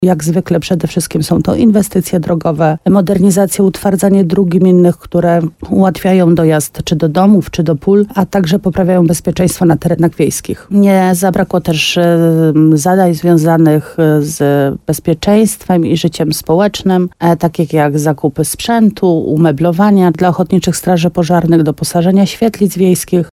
– W Funduszu Sołeckim znalazło się wiele cennych inicjatyw – mówi wójt gminy Łabowa Marta Słaby.